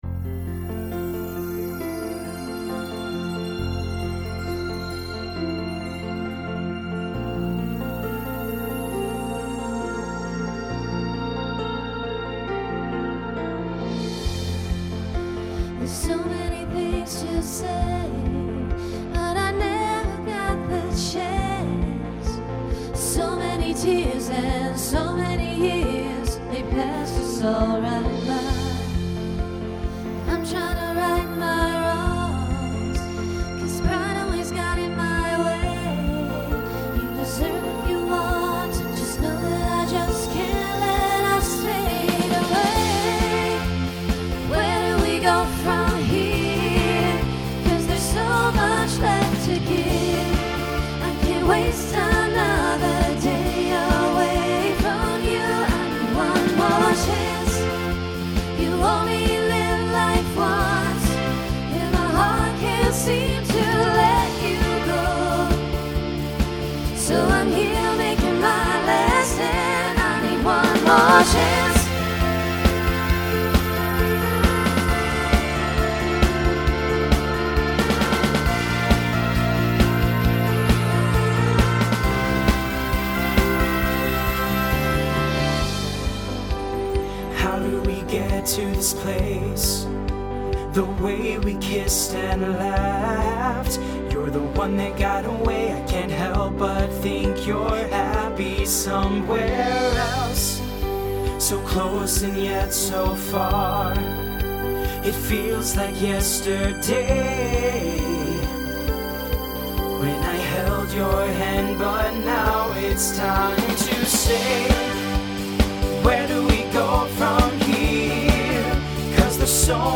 guys/girls feature
Voicing Mixed Instrumental combo Genre Country , Pop/Dance